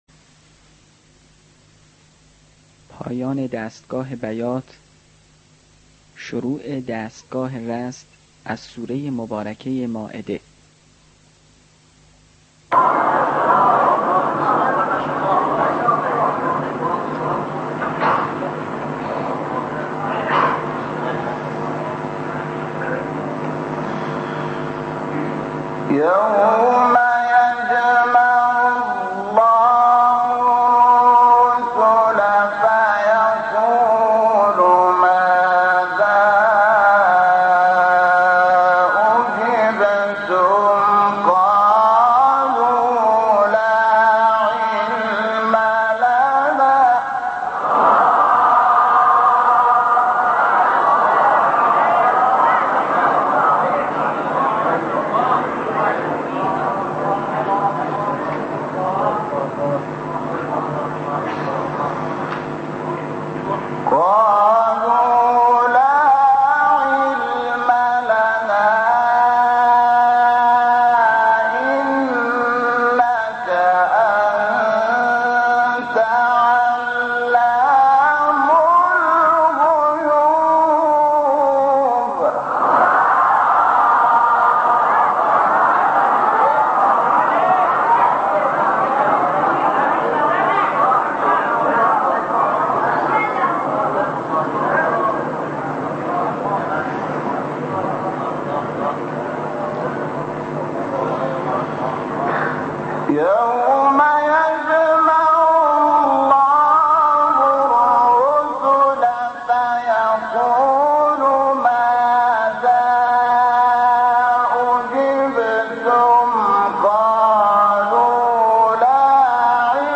سایت قرآن کلام نورانی - شحات - رست (3).mp3